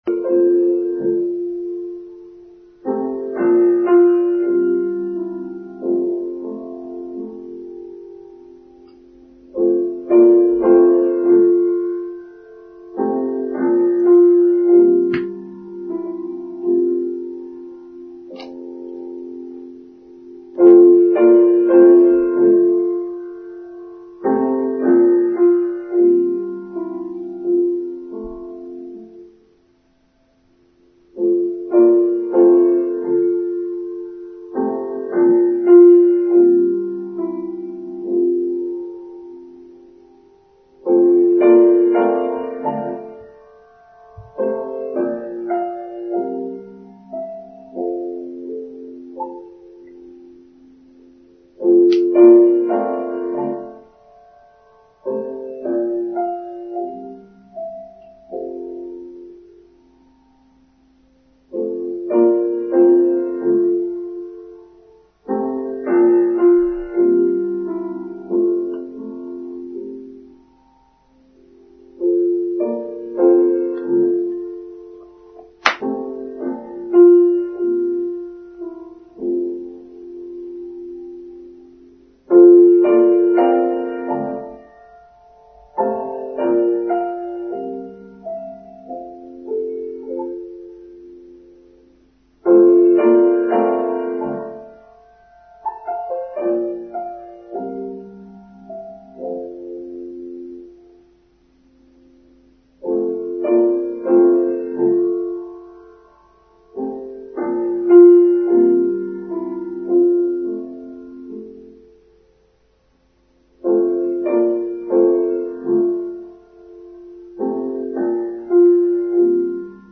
My apologies for the less than perfect sound this week – we forgot to plug the microphone into the laptop…